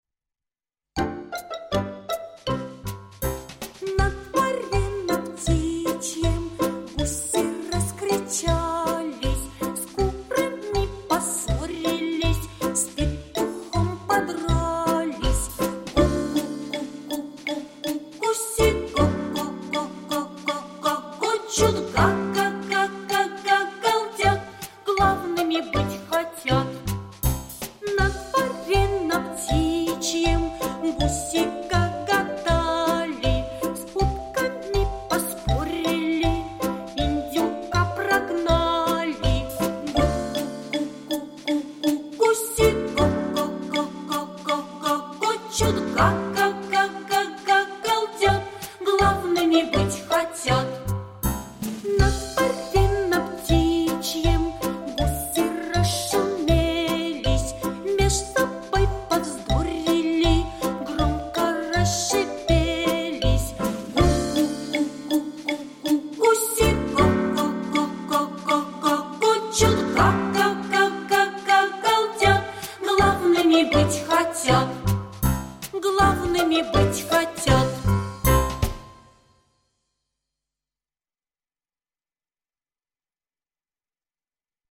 Детская песня